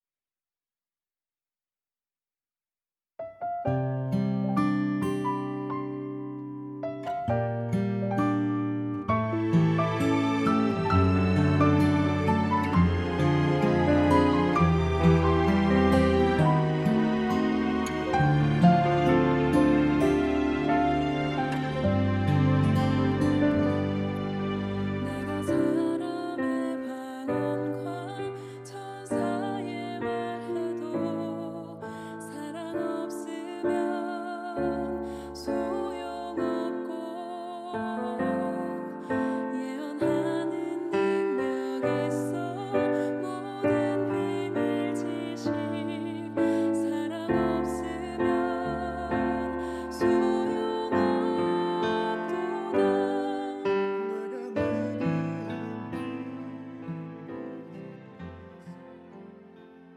음정 -1키
장르 가요 구분
가사 목소리 10프로 포함된 음원입니다